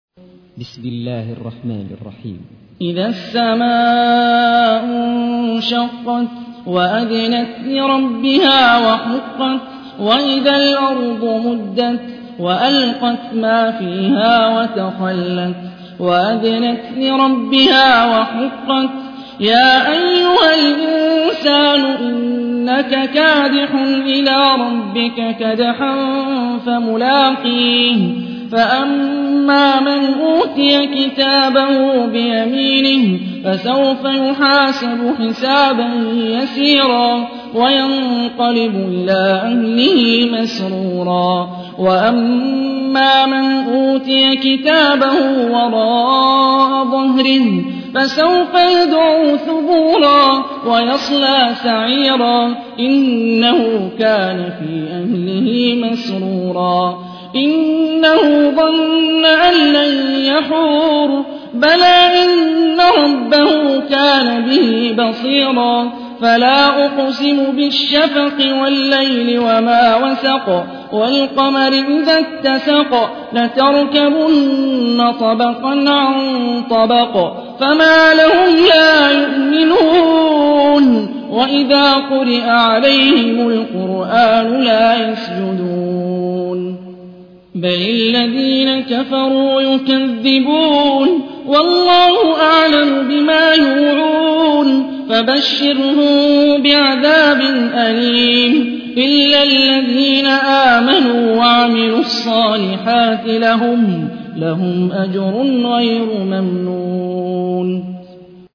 تحميل : 84. سورة الانشقاق / القارئ هاني الرفاعي / القرآن الكريم / موقع يا حسين